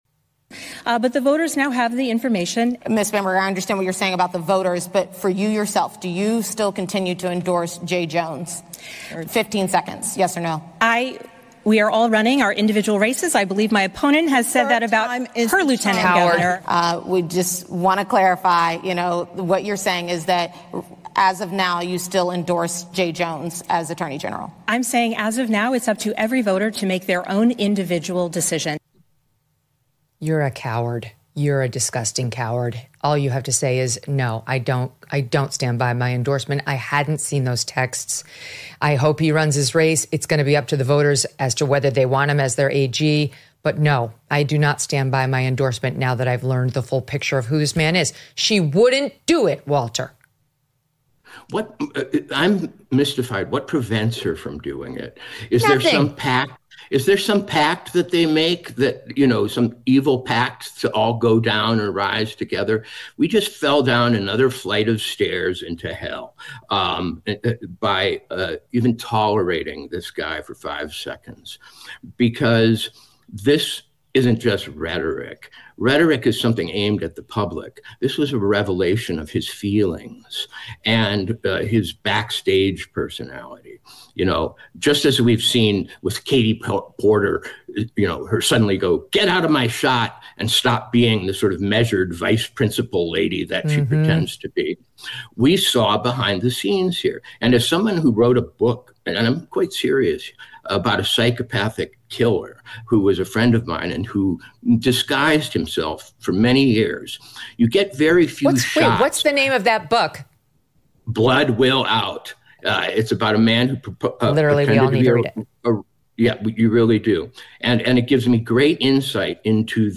In this clip from the Megyn Kelly Show, the first voice you will hear is that of Spanberger in the October 9 debate with Winsome Earle-Sears.